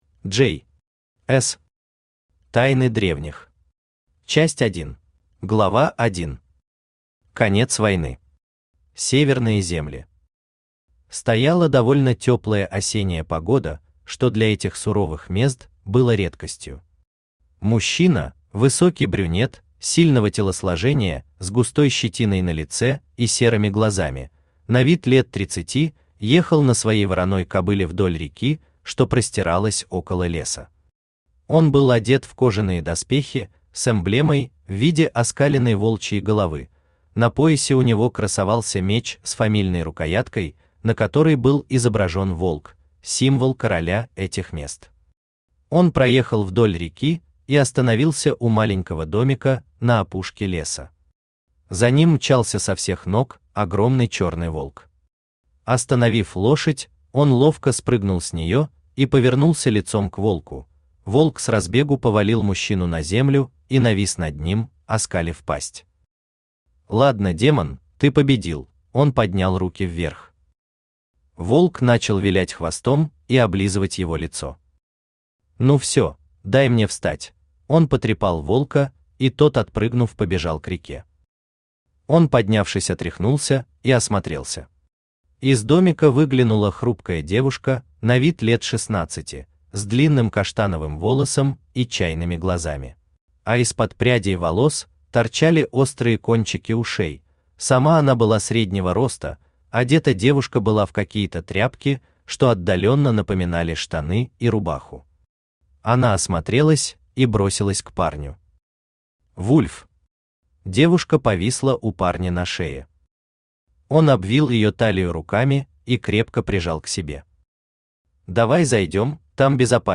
Аудиокнига Тайны Древних. Часть 1 | Библиотека аудиокниг
Читает аудиокнигу Авточтец ЛитРес.